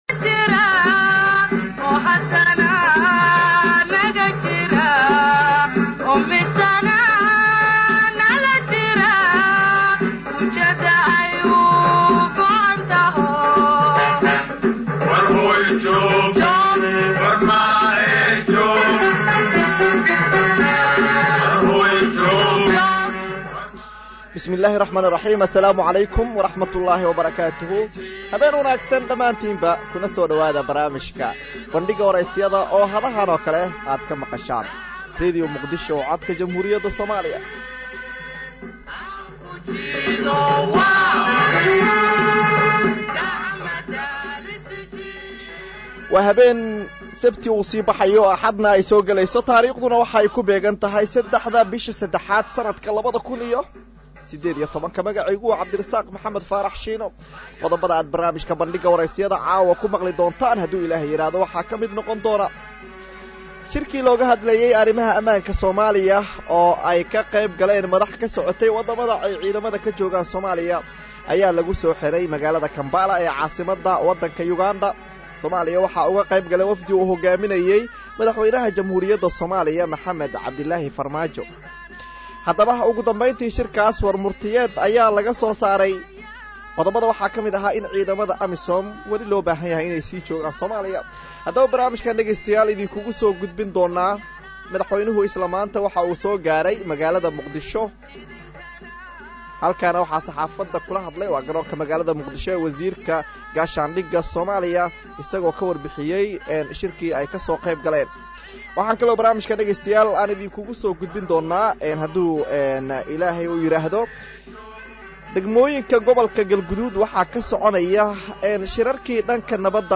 Dhageyso Barnaamijka bandhiga wareysiyada ee Radio Muqdisho